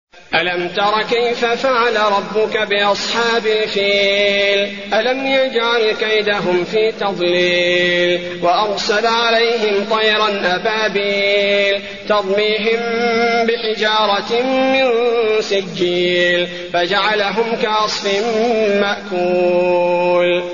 المكان: المسجد النبوي الفيل